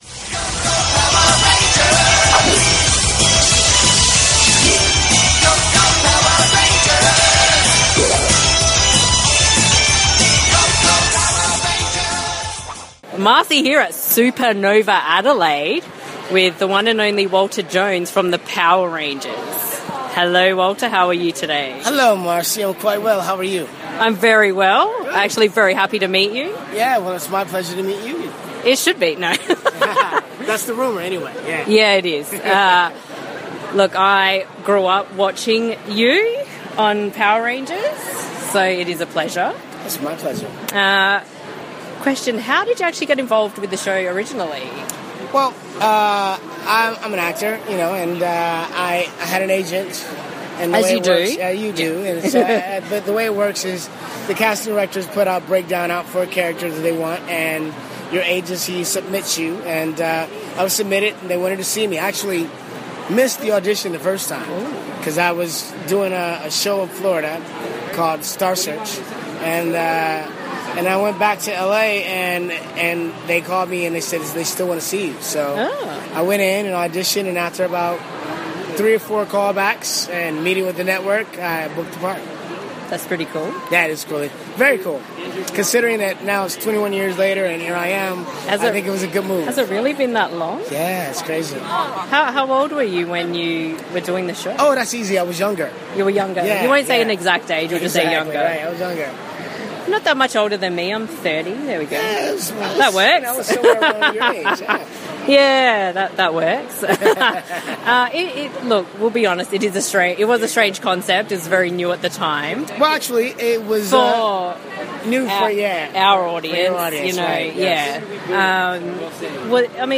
Walter Jones Interview
Supanova Expo held its yearly Adelaide show this past weekend at the Adelaide Showgrounds. We were lucky enough to have a sit down with SupaStar Guest Walter Jones, the original Black Power Ranger.
walter-jones-interview-supanova-adelaide-2014.mp3